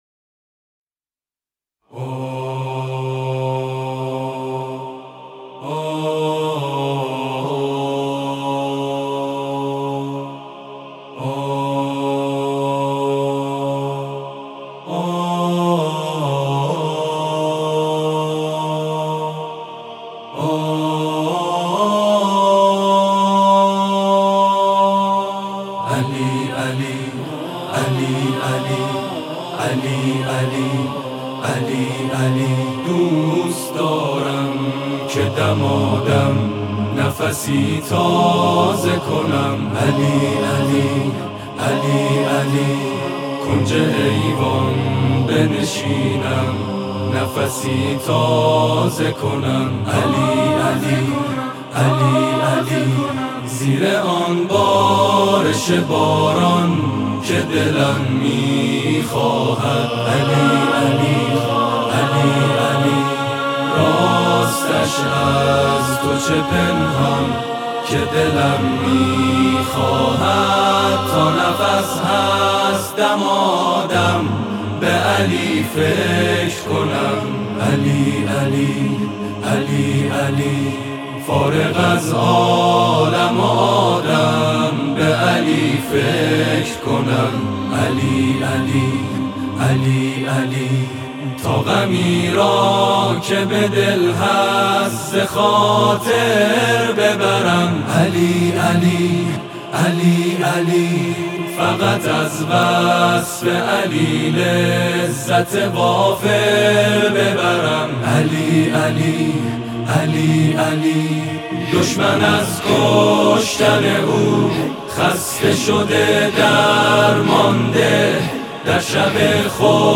عید غدیر رو به همه شما عزیزان تبریک میگم، بهتره تو این ایام شادی ، دل و روحمون رو با یه سرود زیبا شاد کنیم !